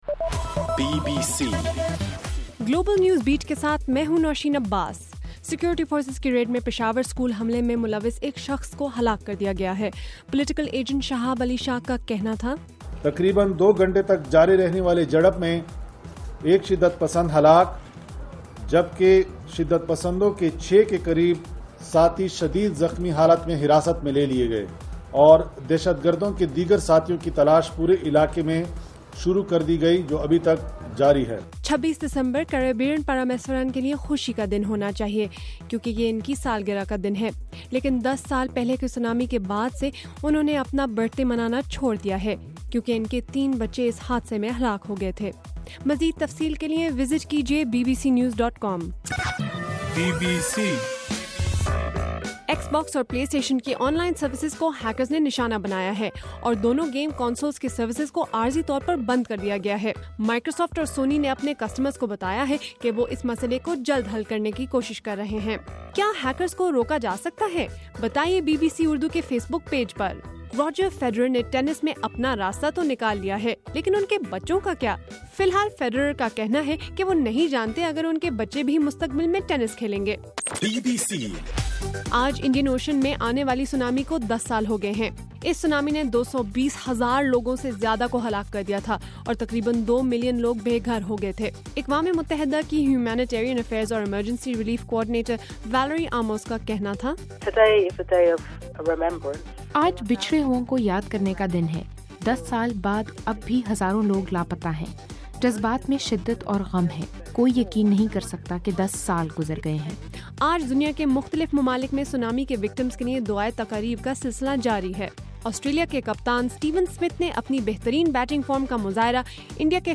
دسمبر 26: رات 11 بجے کا گلوبل نیوز بیٹ بُلیٹن